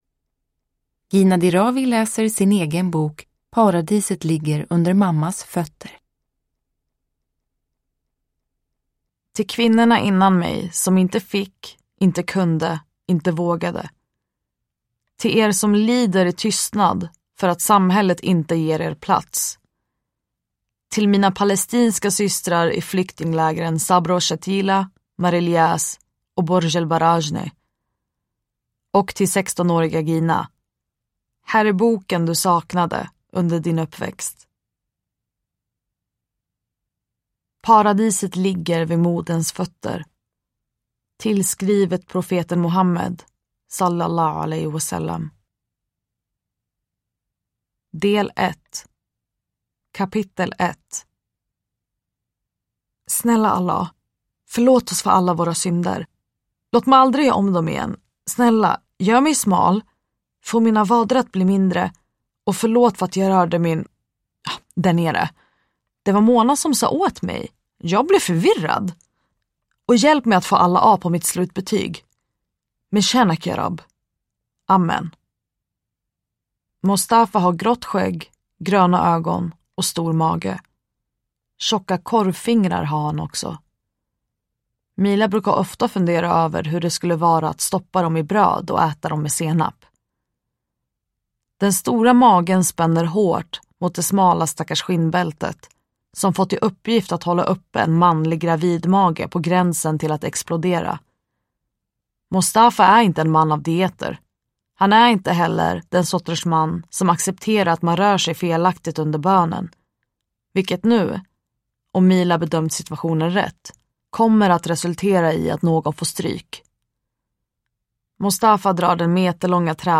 Uppläsare: Gina Dirawi